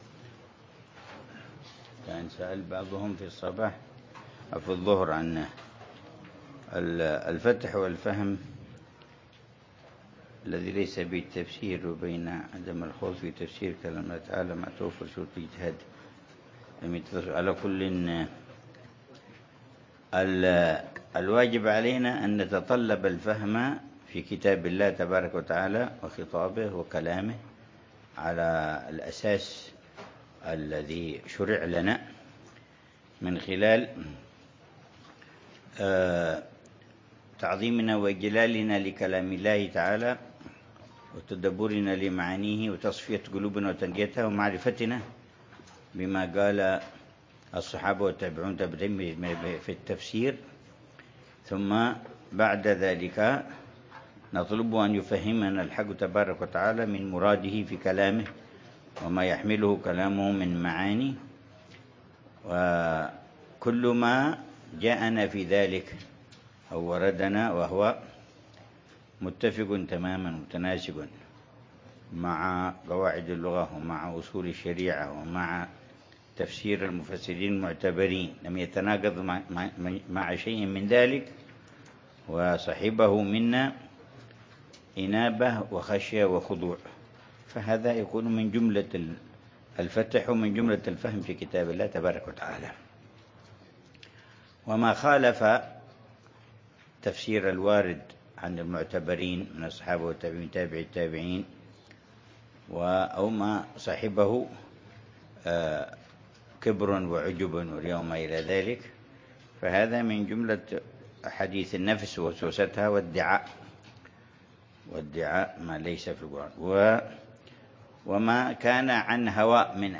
الدرس العاشر للعلامة الحبيب عمر بن محمد بن حفيظ في شرح كتاب: الأربعين في أصول الدين، للإمام الغزالي .